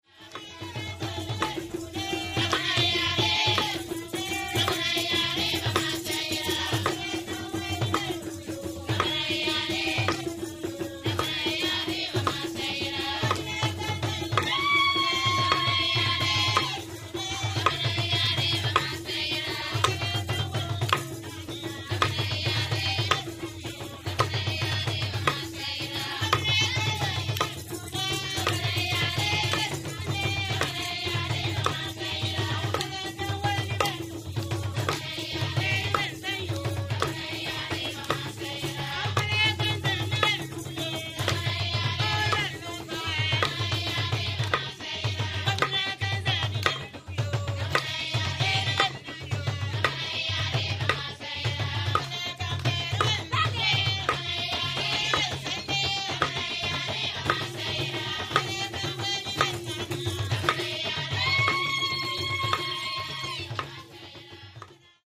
The kori is a closed pot-shaped gourd drum, the membrane of which is stretched over the opening of a gourd and tightened by means of ropes and pegs.
The korbala drum has a diameter of 50 cm and a depth of 30 cm and has a high clear tone. The kornia drum has a diameter of 70 cm and a depth of 50 cm and has a low but still fairly clear tone.
The kori drum is played with the hands, the palm of the hand and the fingertips.